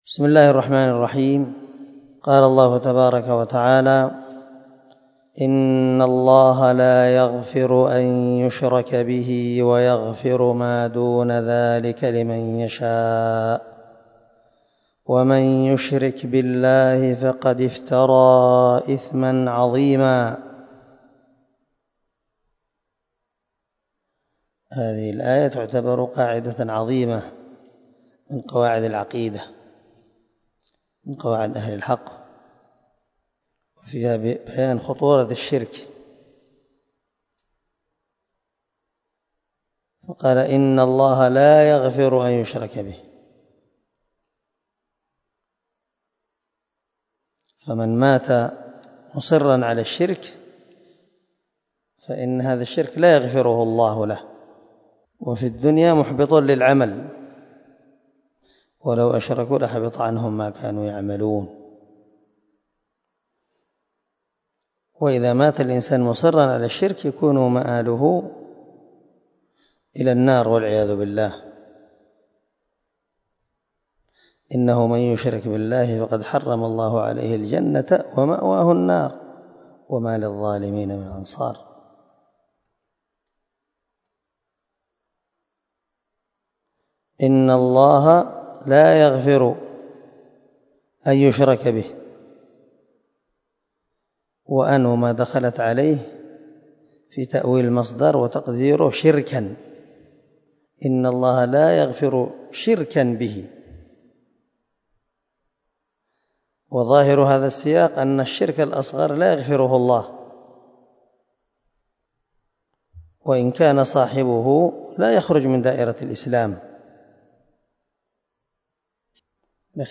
269الدرس 37 تفسير آية ( 48 ) من سورة النساء من تفسير القران الكريم مع قراءة لتفسير السعدي